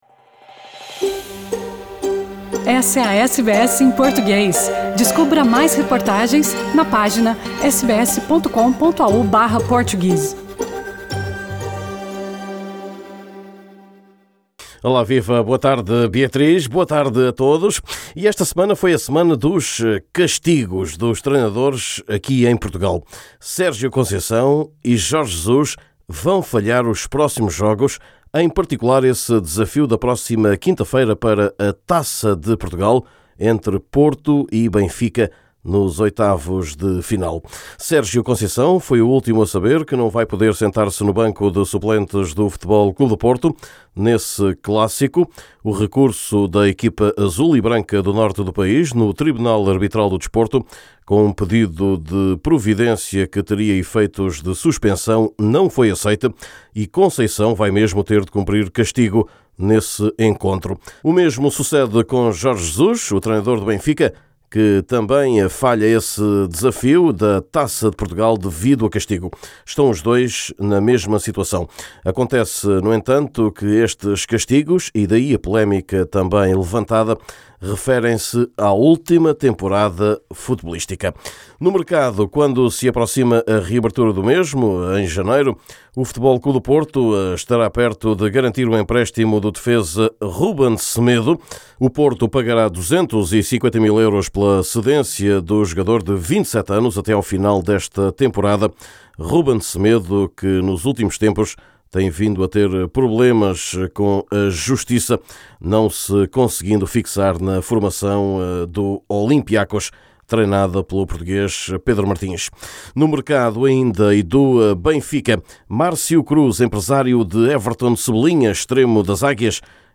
Neste boletim